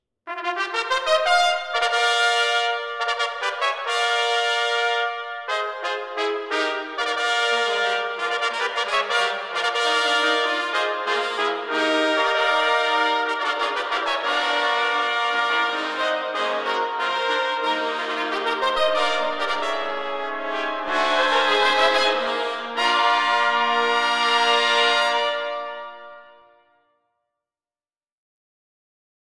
Number of Trumpets: 9
Key: Bb concert
The virtual recording